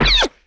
impact_projectile_002.wav